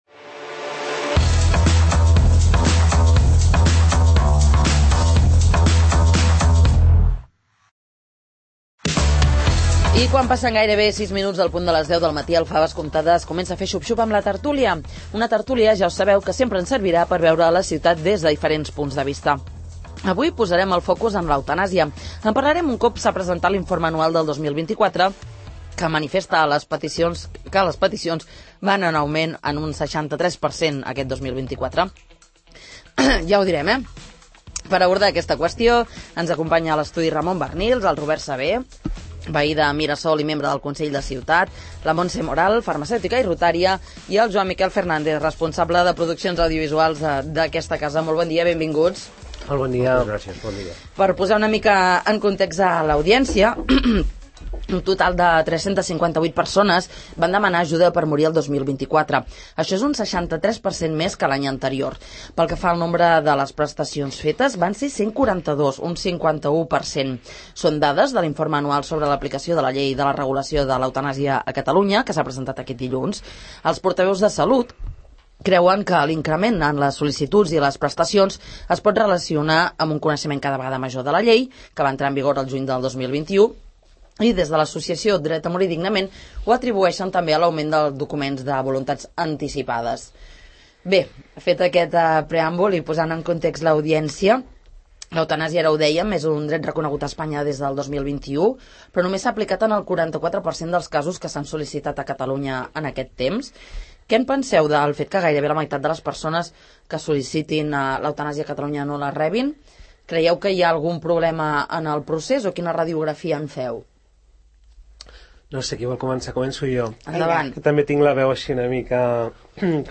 En quin punt est� la llei de l'eutan�sia? A debat a la tert�lia del 'Faves Comptades'